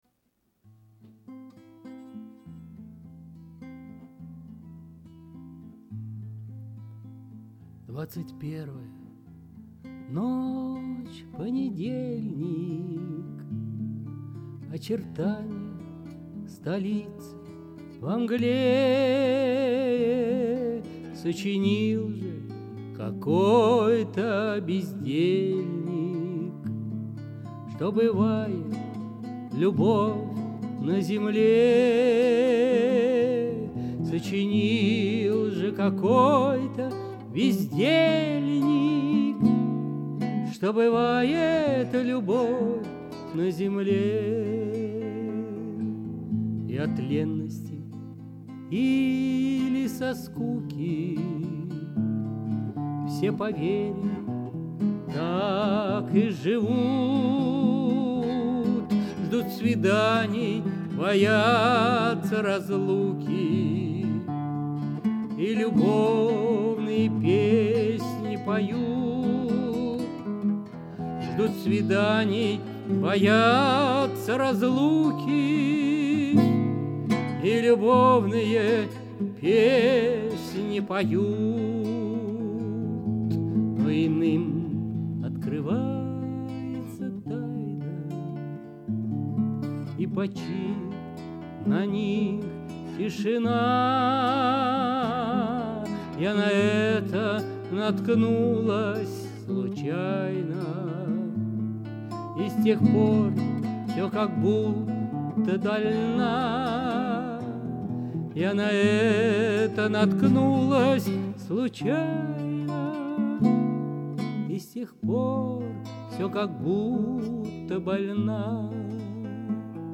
Понедельник…» однажды написался романс под названием «Бывает ли любовь на земле».